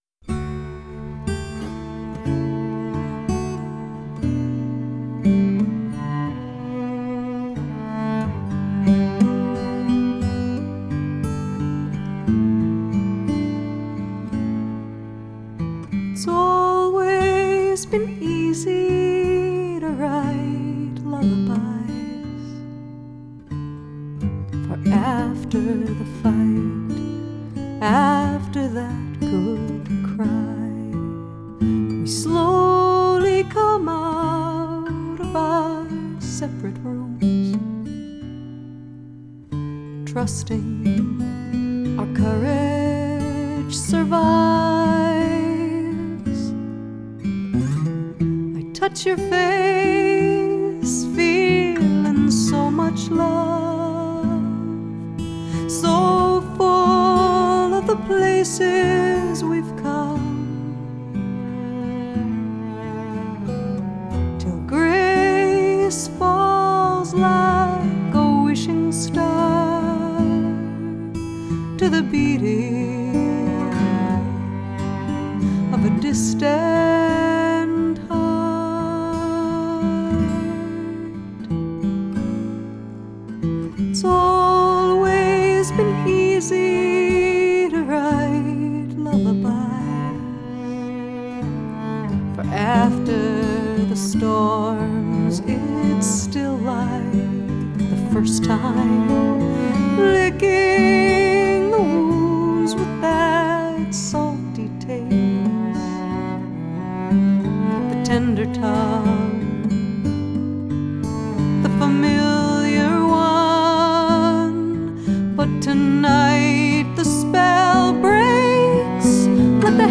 evocative vocal
warm expressive cello